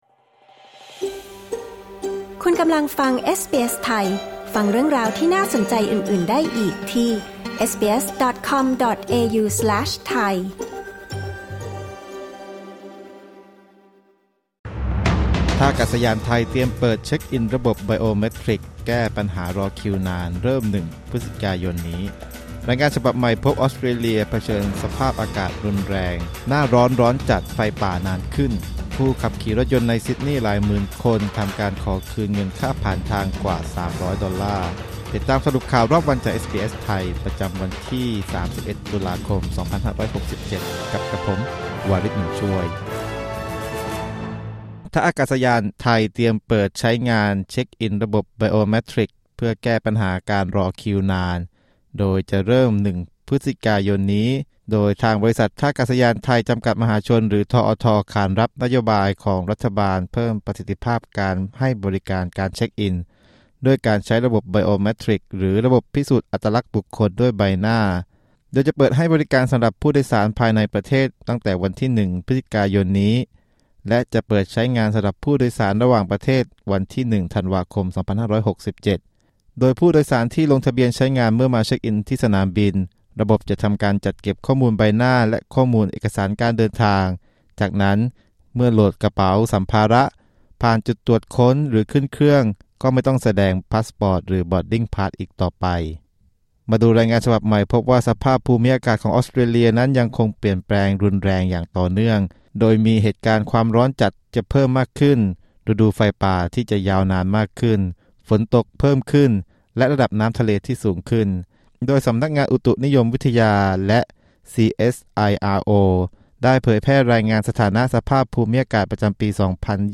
สรุปข่าวรอบวัน 31 ตุลาคม 2567